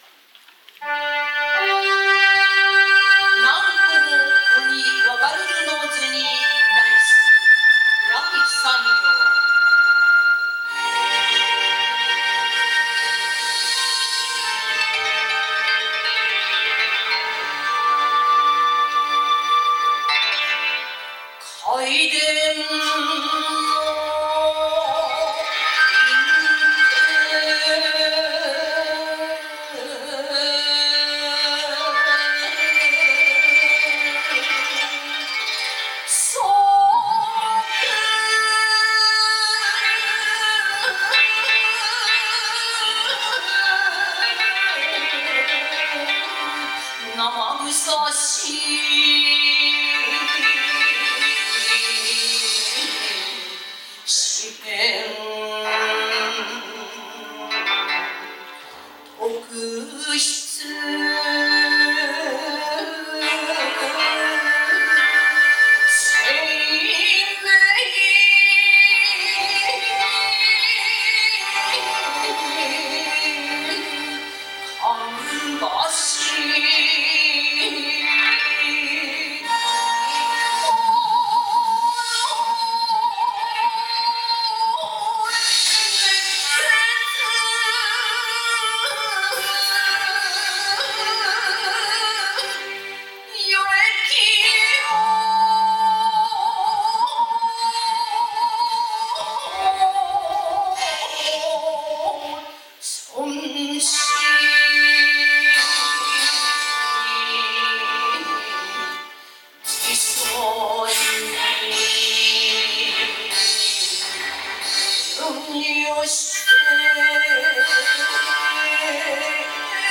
そして正午より第５０回吟士権コンクール決勝大会が行われました。